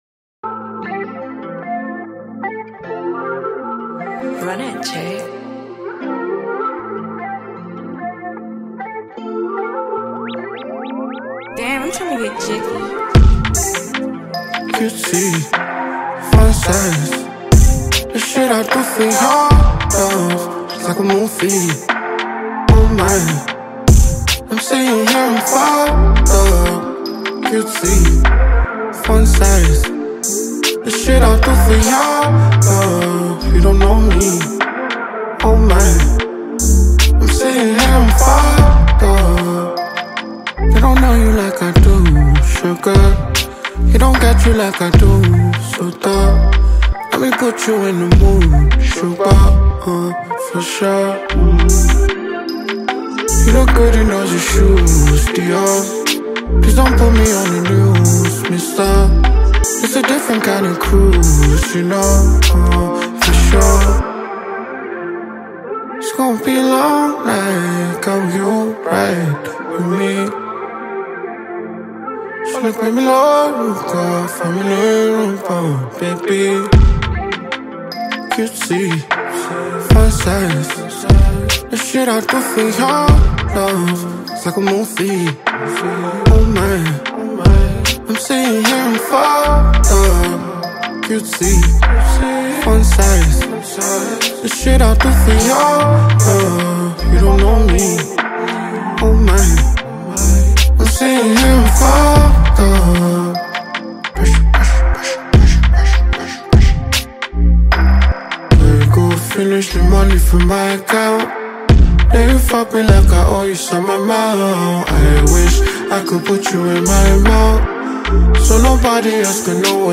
bringing vibrant energy and smooth vibes
Afrobeats